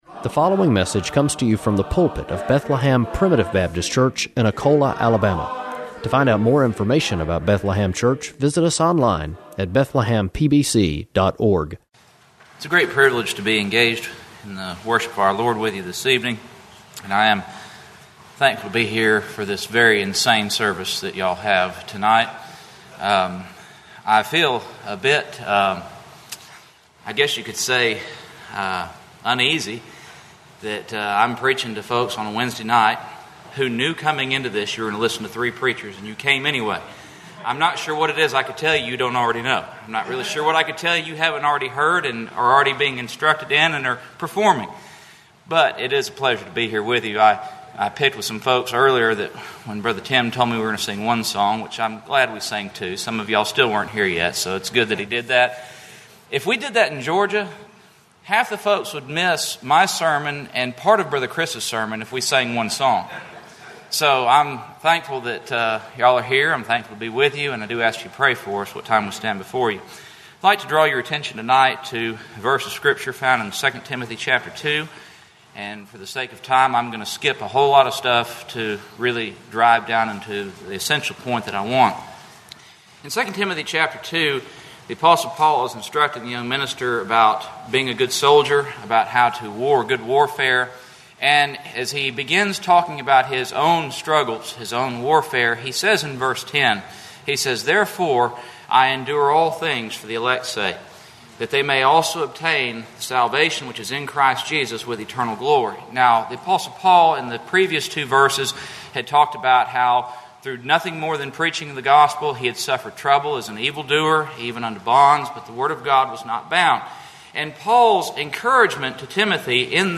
In this Wednesday night service